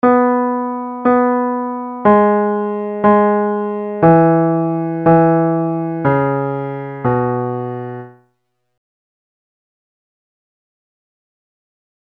Key written in: E Major
Comments: Nice gentle reassuring tag.
Each recording below is single part only.